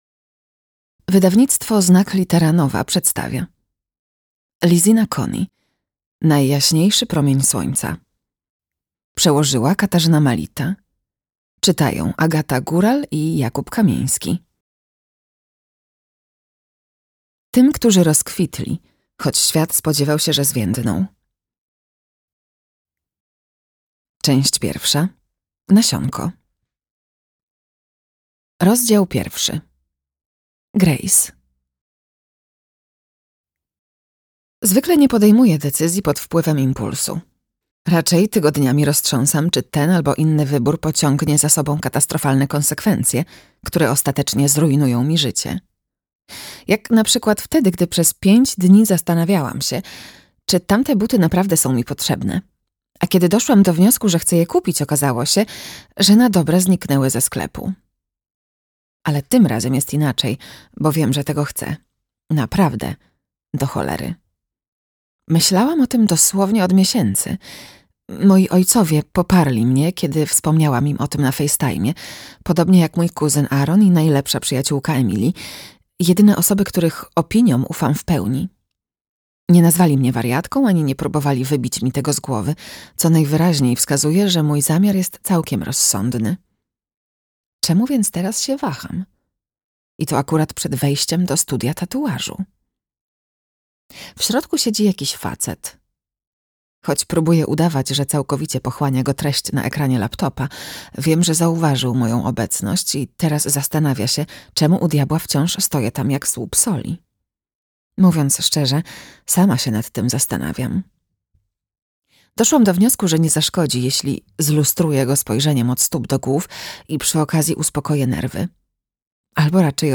Najjaśniejszy promień słońca - Lisina Coney - audiobook